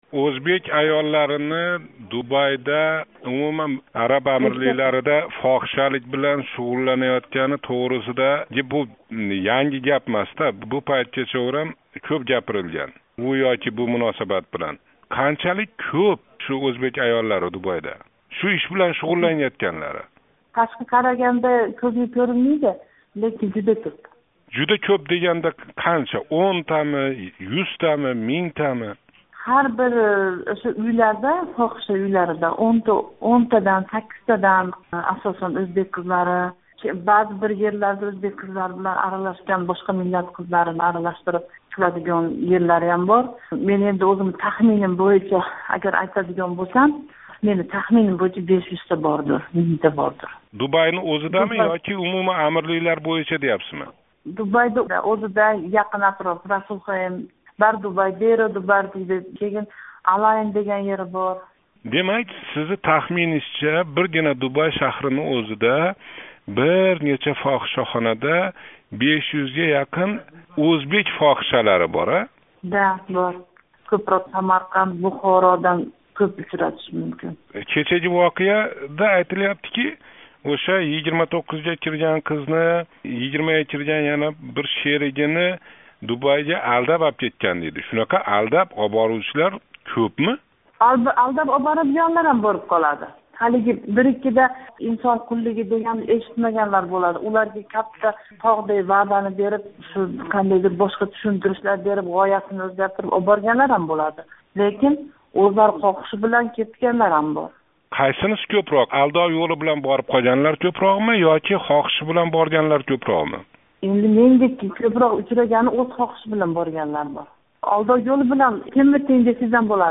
Дубайдаги вазиятни биладиган аëл билан суҳбат